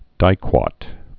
(dīkwät)